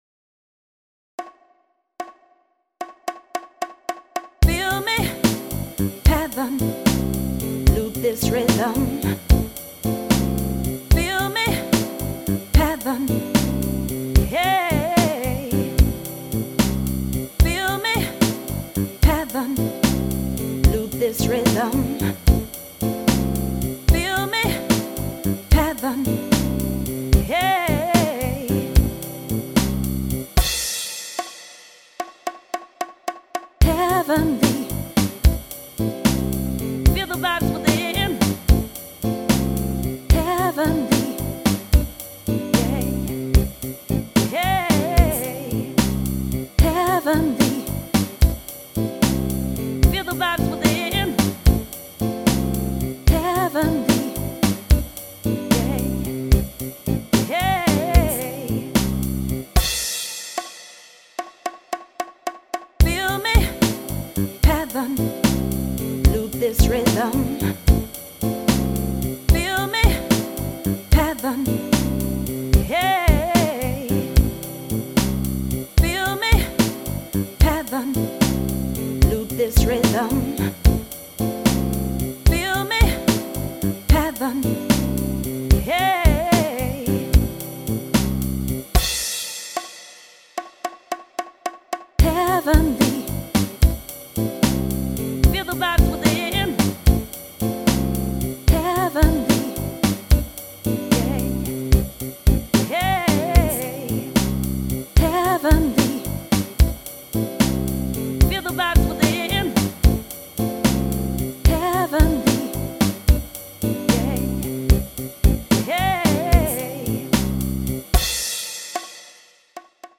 Sound Sample: 12/8 Grooves